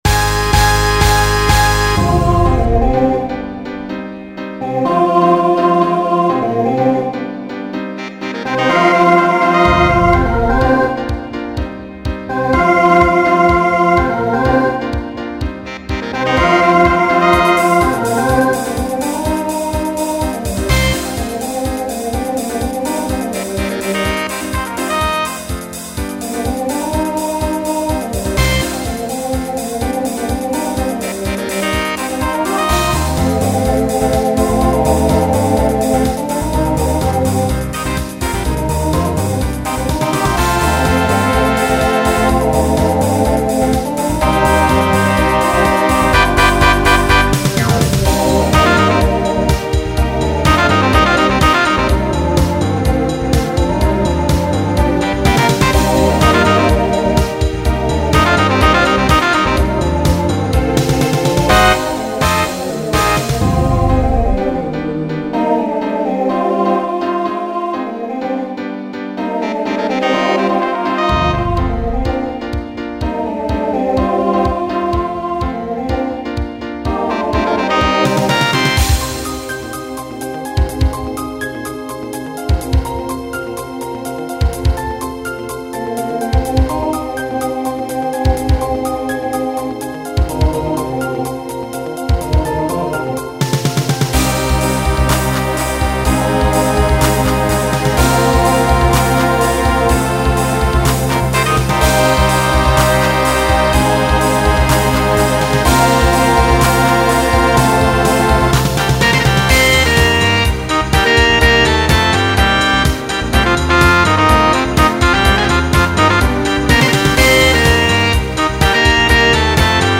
Genre Pop/Dance
Transition Voicing TTB